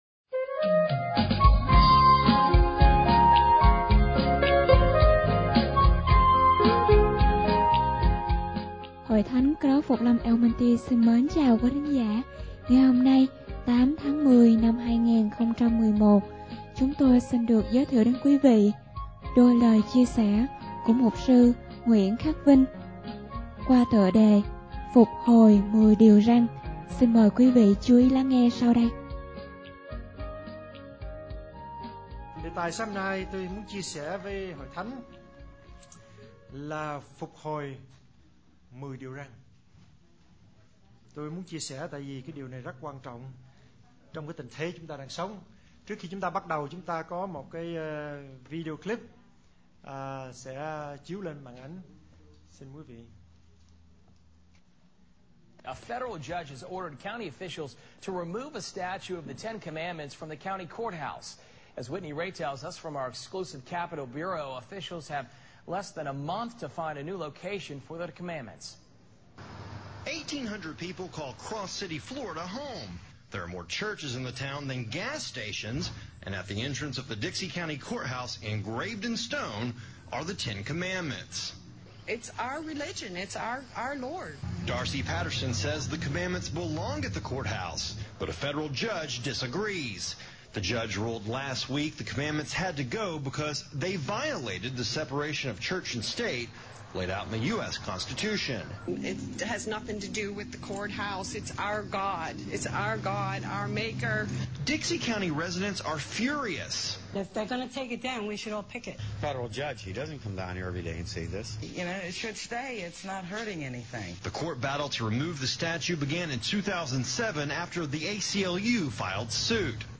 Sermon / Bài Giảng